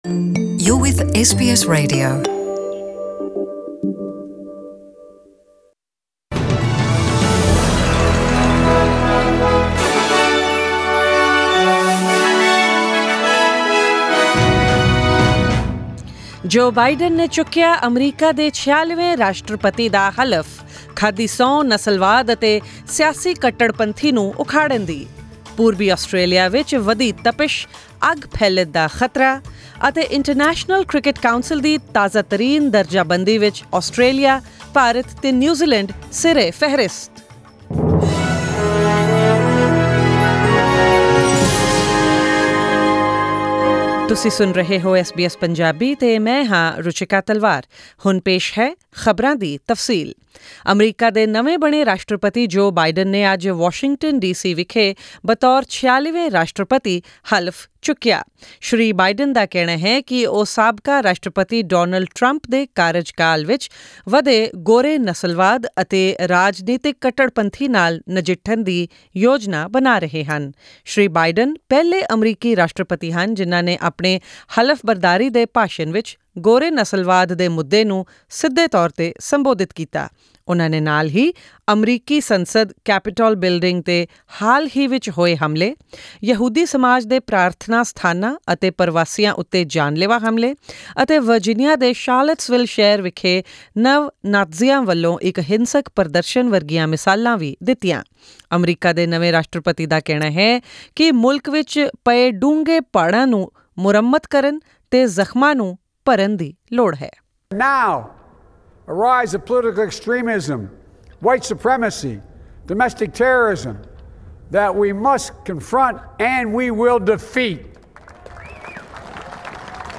As Joe Biden took oath as the 46th US president, Vice-President Kamala Harris created history as the first woman, person of colour and South Asian heritage to hold office. Tune into tonight's bulletin for more news stories, sports updates, currency exchange rates and the weather forecast for tomorrow.